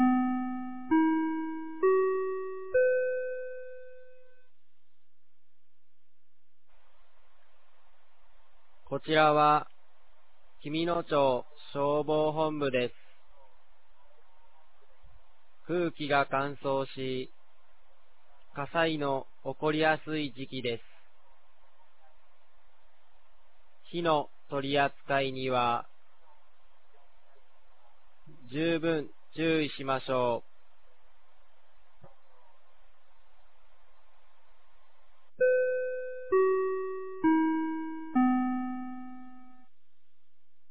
2024年11月30日 16時00分に、紀美野町より全地区へ放送がありました。